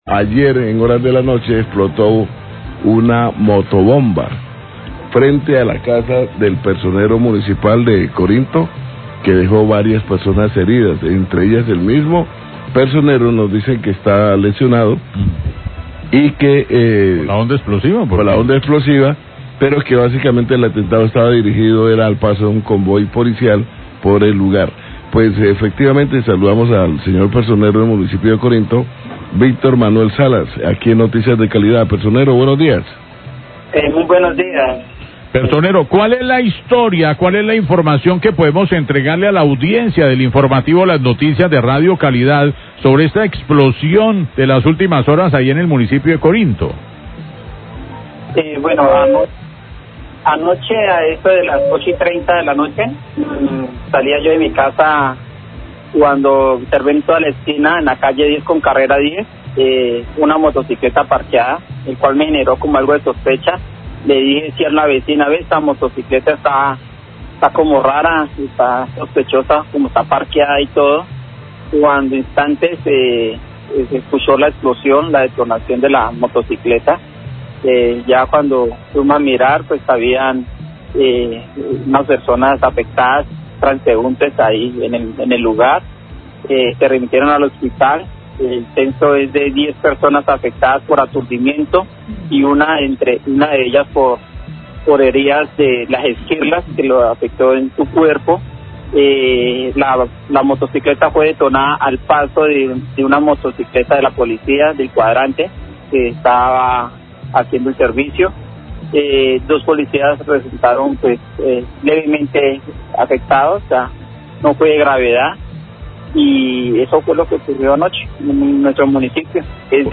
Entrevista con el Personero de Corinto quien habla del atentado con motobomba a una patrulla de la policía frente a sus casa. Comenta que horas antes se habían presentado cortes de energía lo que motivo a que, por fortuna, los habitantes estuvieran en sus casas y así no se presentarán más heridos.